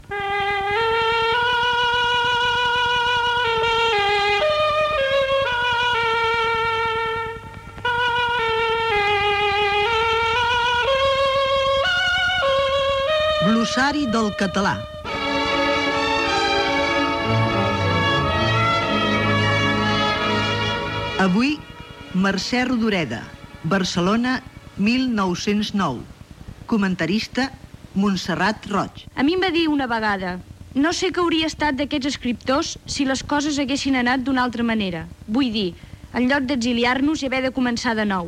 Careta del programa i espai dedicat a l'escriptora Mercè Rodoreda fet per l'escriptora i periodista Montserrat Roig.
Extret de Crònica Sentimental de Ràdio Barcelona emesa el dia 5 de novembre de 1994.